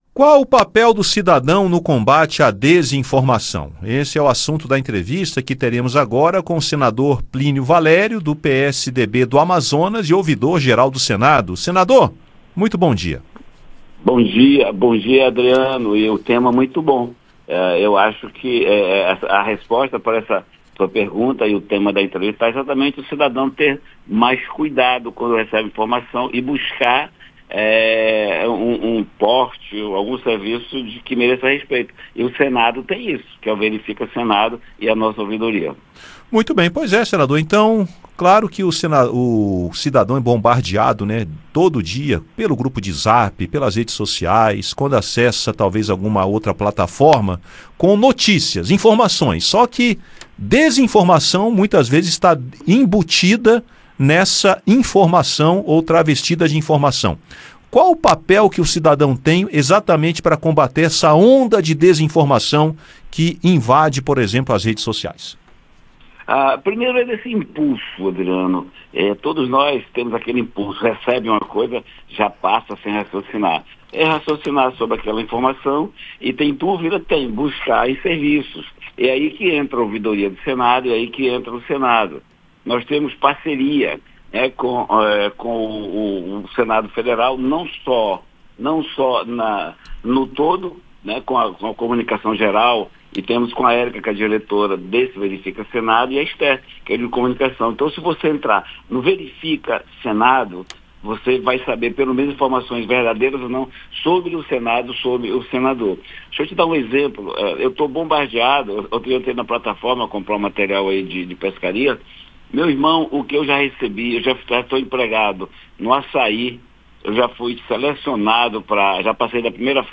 O senador Plínio Valério (PSDB-AM), que coordena os trabalhos Ouvidoria do Senado Federal, em entrevista ao Conexão Senado, fala sobre o importante papel do cidadão no combate à desinformação. Ele destaca as principais demandas com notícias falsas, como a população pode ajudar no combate às fake news e de que forma a Ouvidoria do Senado colabora nessa luta.